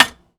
R - Foley 101.wav